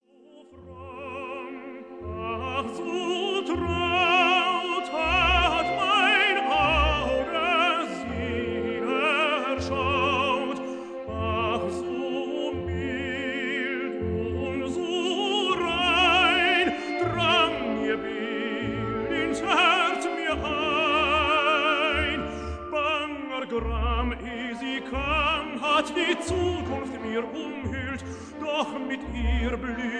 tenor
Stereo recording made in Berlin May 1960